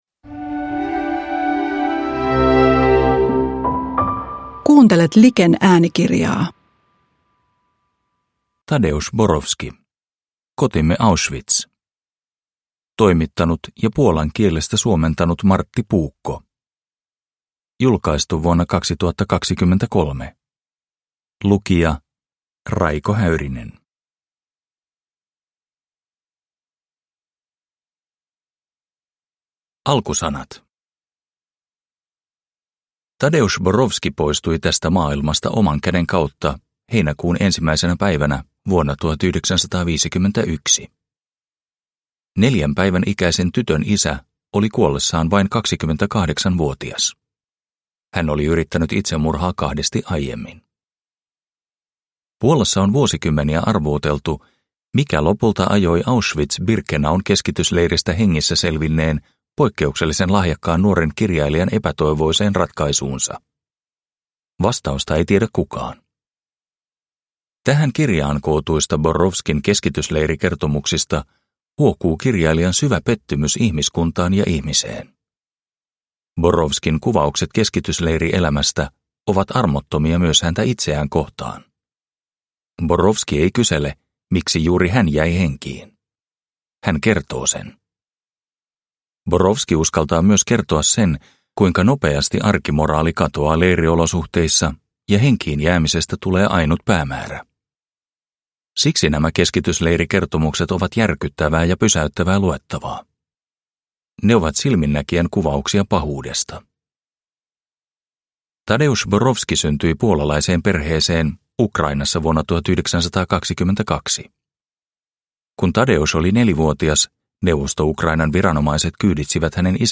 Kotimme Auschwitz – Ljudbok – Laddas ner